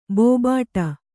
♪ bōbāṭa